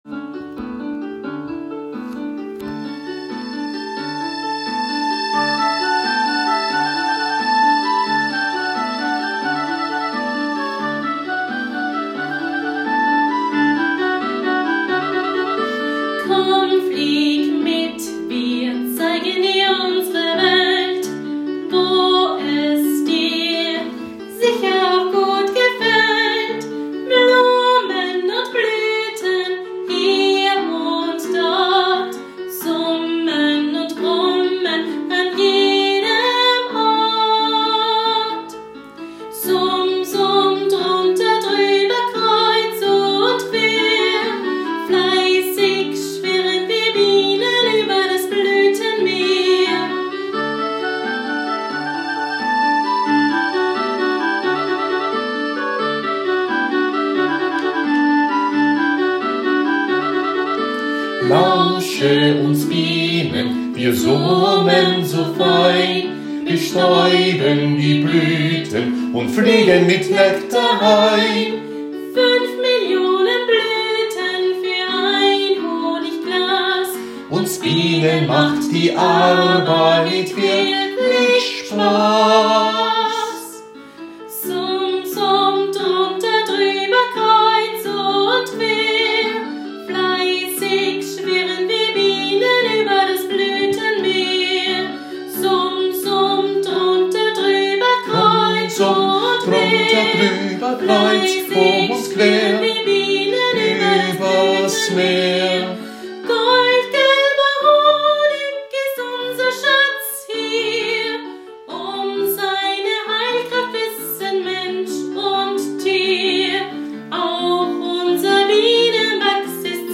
Es singen, tanzen, schauspielern und musizieren rund 150 Kinder aus den Regionsgemeinden,